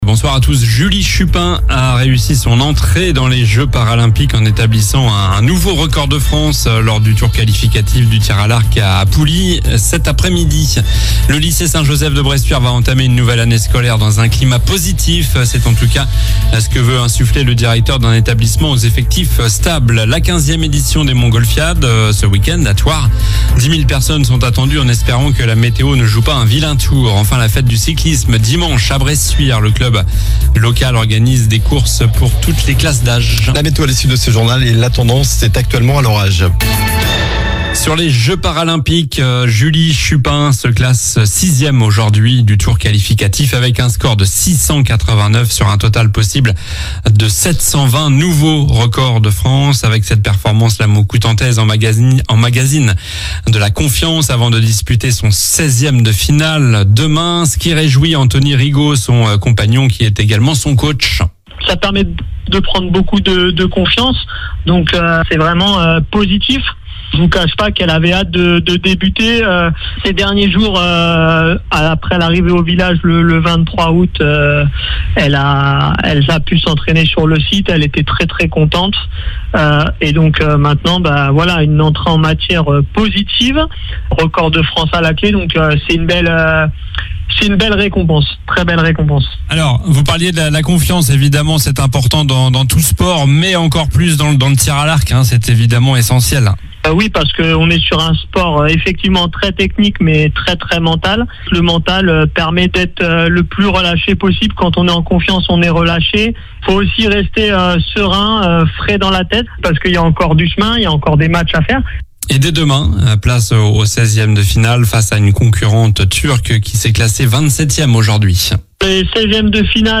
Journal du jeudi 29 août (soir)